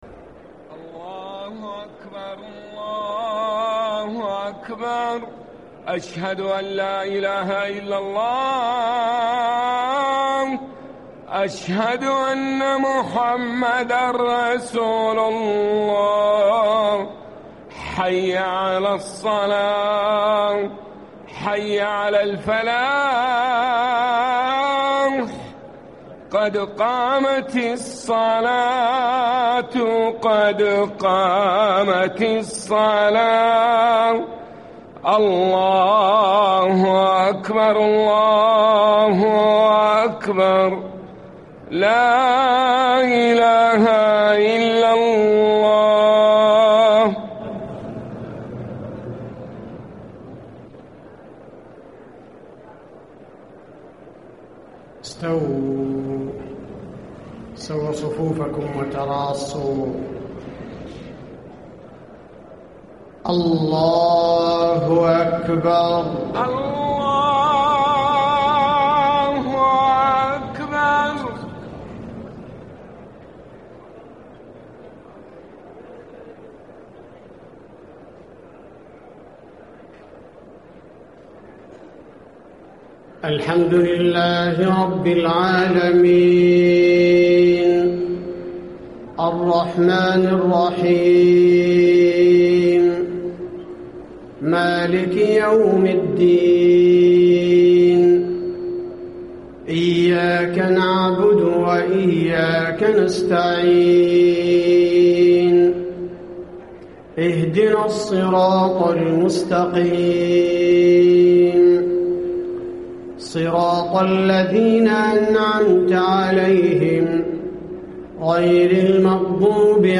صلاة العشاء 9-6-1435 ما تيسر من سورة الفتح > 1435 🕌 > الفروض - تلاوات الحرمين